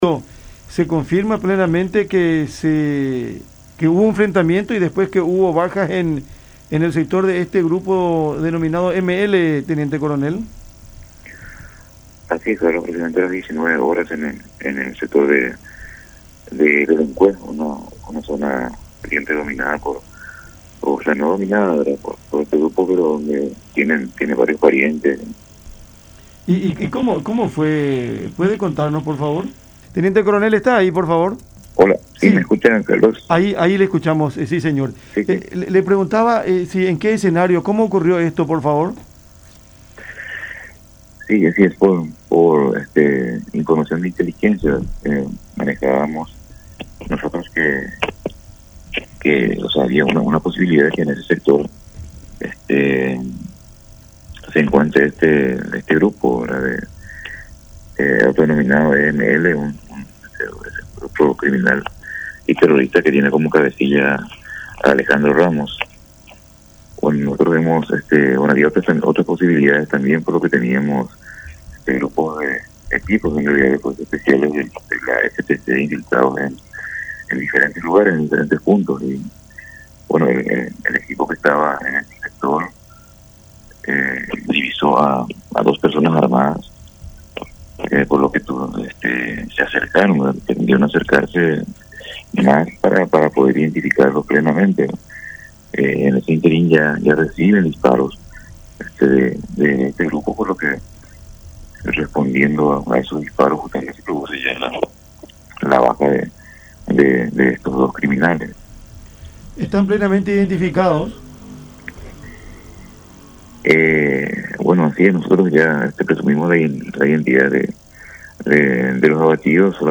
en diálogo con Cada Mañana por La Unión.